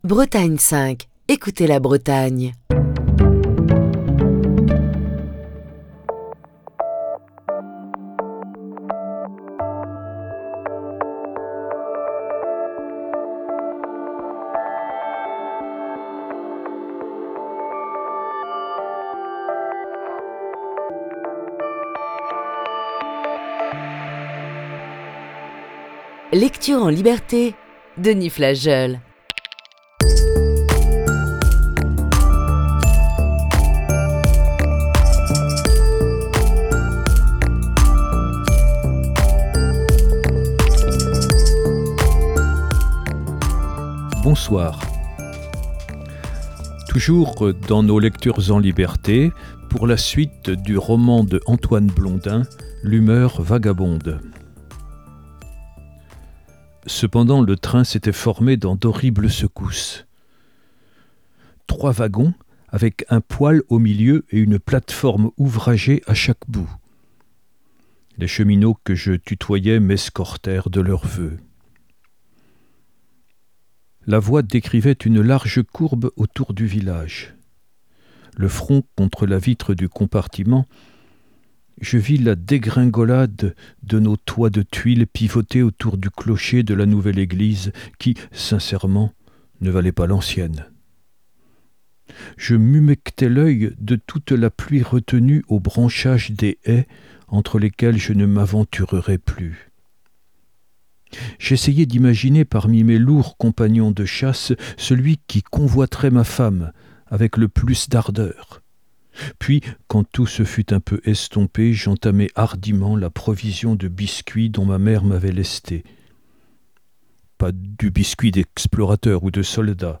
lit le roman d'Antoine Blondin "L'humeur vagabonde". Voici ce soir la deuxième partie de ce récit.